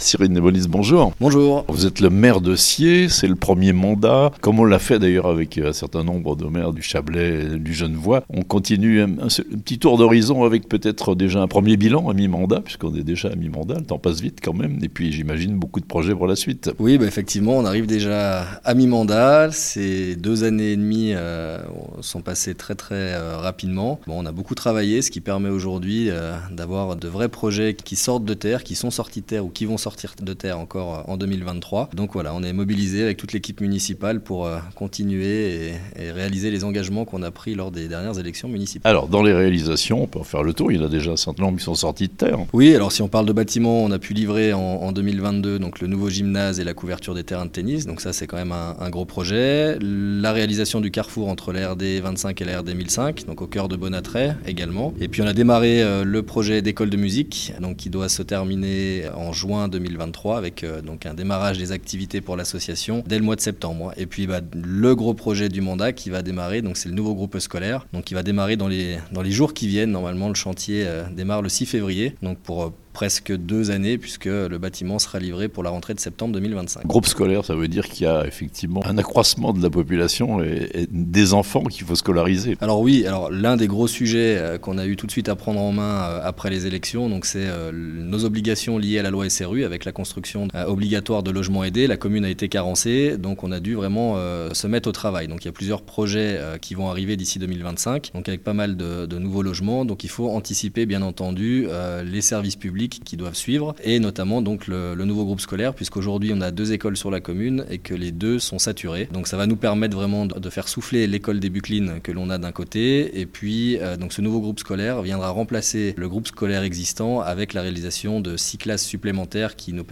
Cyril Démolis, Maire de Sciez.